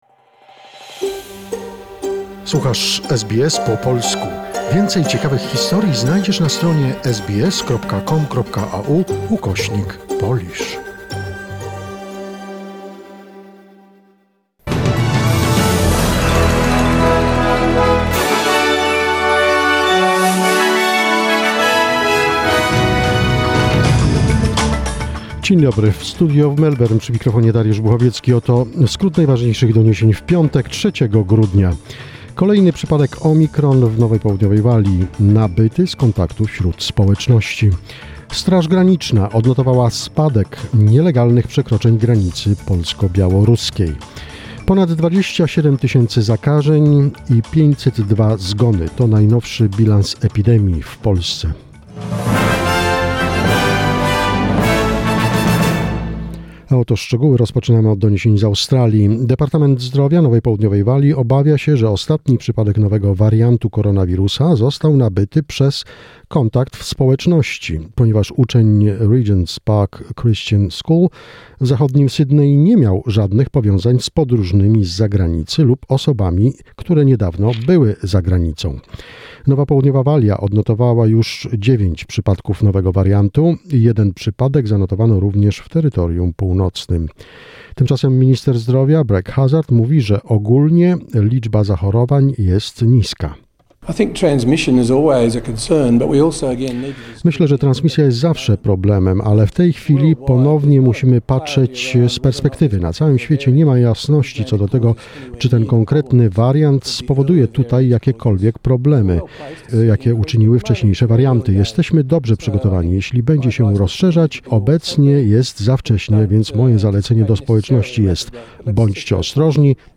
SBS News in Polish, 3 December 2021